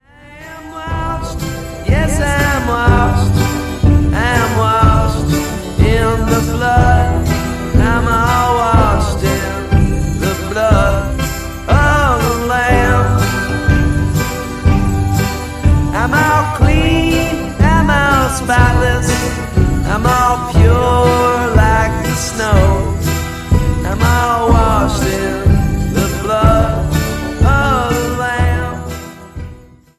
10s Alternative Music